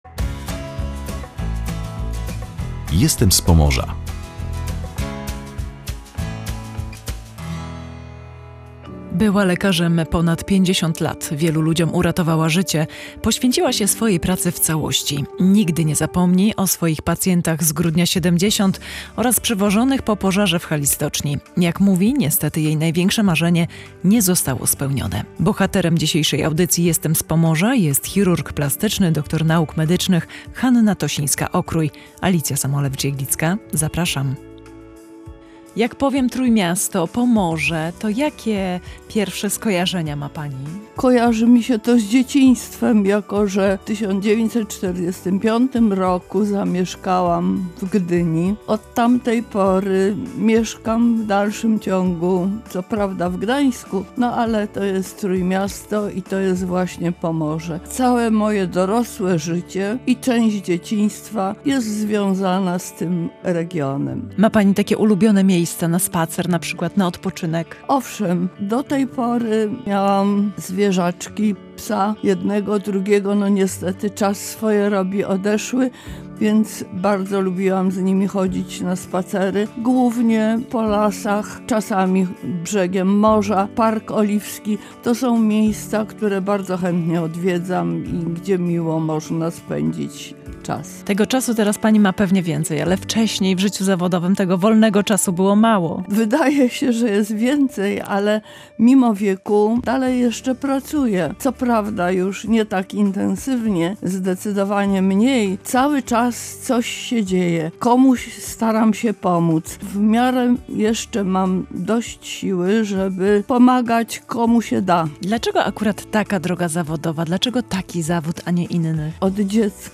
W rozmowie podkreśla, że niestety jej największe marzenie nie zostało spełnione.